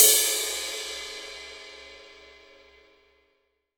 Index of /90_sSampleCDs/AKAI S6000 CD-ROM - Volume 3/Crash_Cymbal1/16-17_INCH_CRASH